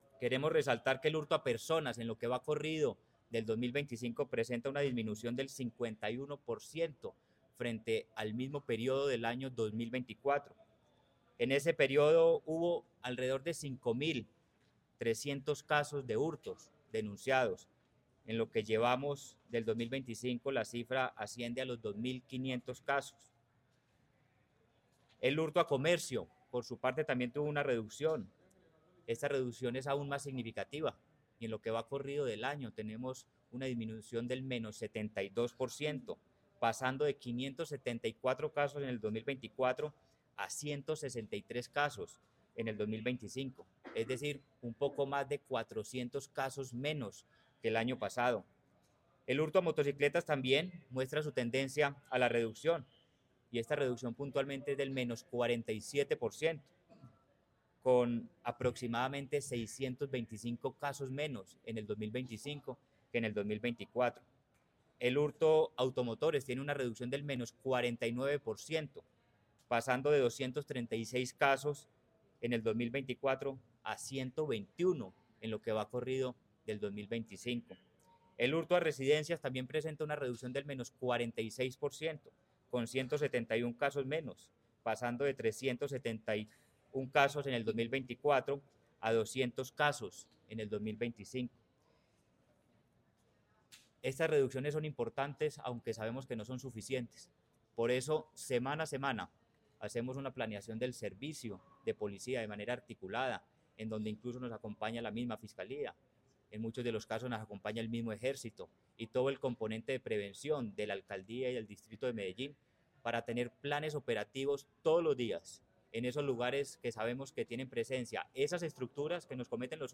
Palabras de Manuel Villa Mejía, secretario de Seguridad y Convivencia Medellín continúa consolidando avances en materia de seguridad, con una reducción del hurto en todas sus modalidades.